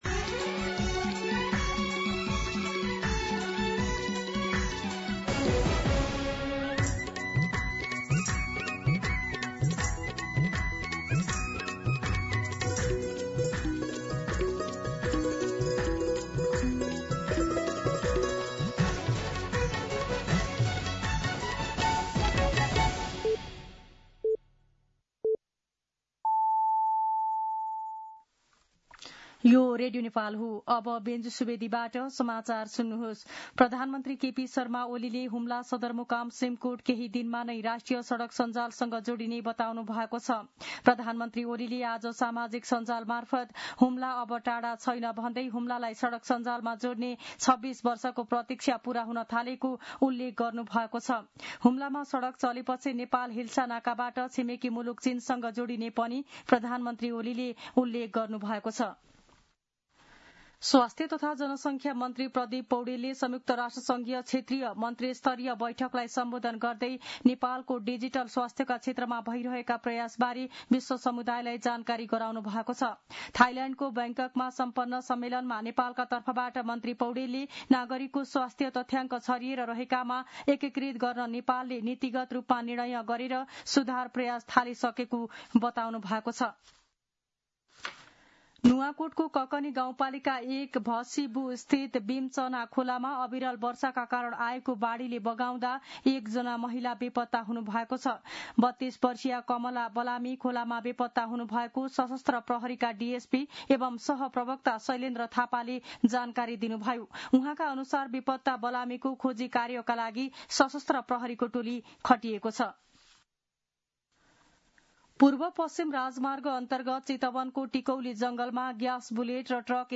मध्यान्ह १२ बजेको नेपाली समाचार : १३ असार , २०८२